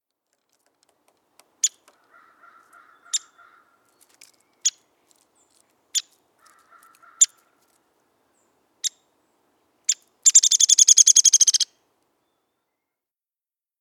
Downy Woodpecker
How they sound: Downy Woodpeckers give off a high-pitched pik note followed by a descending whinny call. Although they don’t sing songs, they drum loudly against pieces of wood or metal to achieve the same effect.